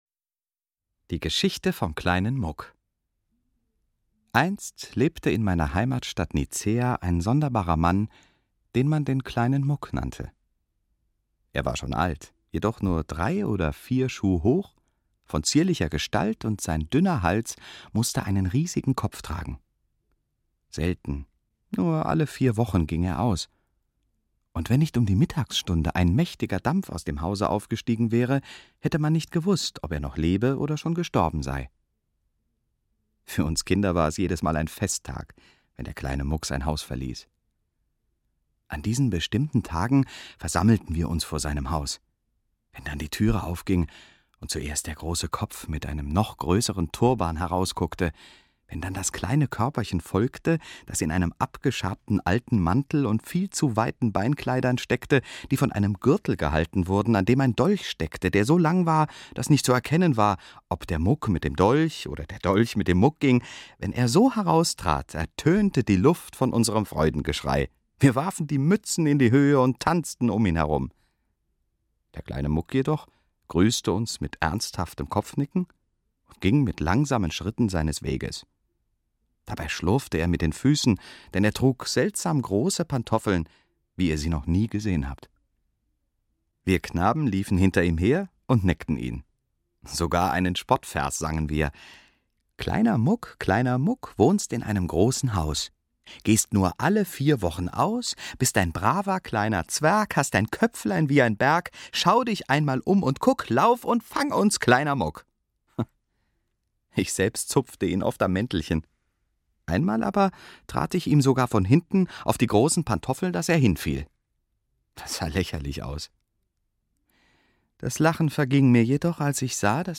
Fagott und Saxophon
Marimbaphon
Klavier
Erzähler